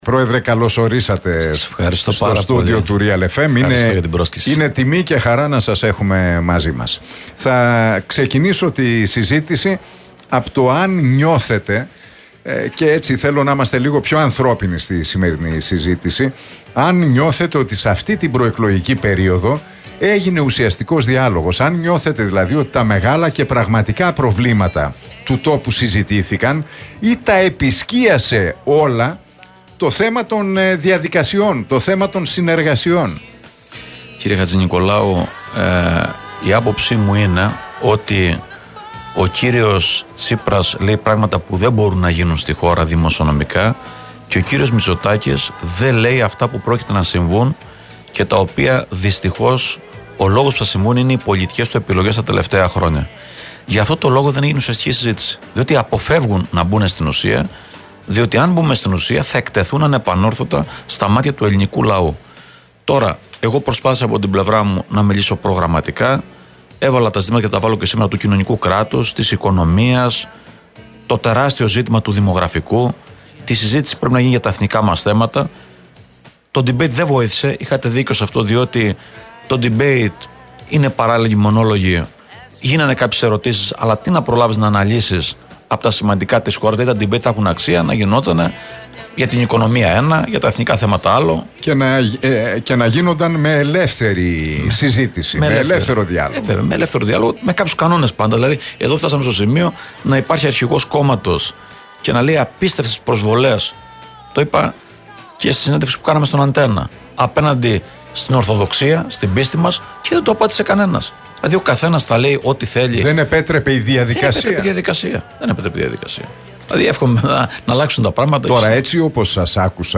Ο Νίκος Ανδρουλάκης στο στούντιο του Realfm 97,8: Όχι σε κυβέρνηση ειδικού σκοπού - Τι είπε για Μητσοτάκη και Τσίπρα
Ο πρόεδρος του ΠΑΣΟΚ-ΚΙΝΑΛ Νίκος Ανδρουλάκης βρέθηκε στο στούντιο του Realfm 97,8 και παραχώρησε μια συνέντευξη εφ' όλης της ύλης στον Νίκο Χατζηνικολάου.